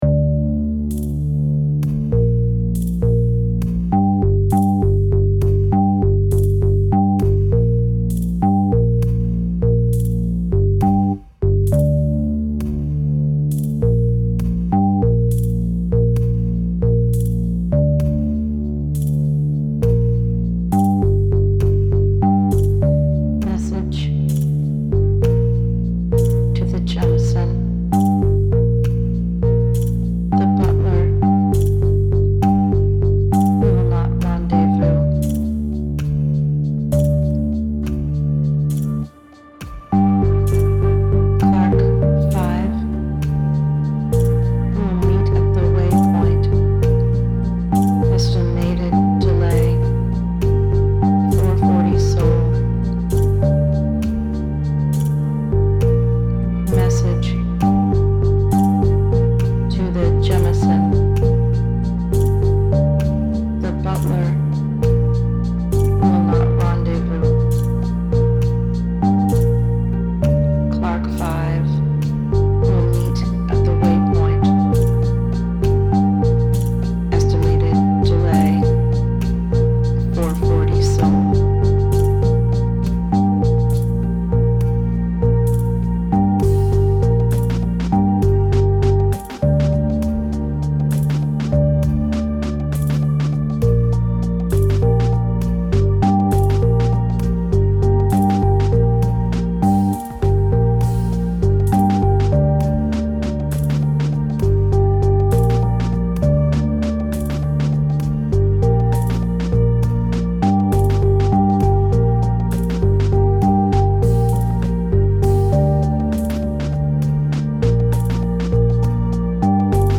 In this continuation of the space story, a message is sent out over the distance in a classic format — morse code.
I assigned a three-beat value to the dash and a one-beat value to the dots.
The result is oddly rhythmic to me, and kind of trancelike. I layered the message against other sections to provide more depth. I included a brief vocal message to accompany it as well, but it’s far from the focus of this piece.
Lacking a mig engine to play on, I had to make due with some crystal bell synths.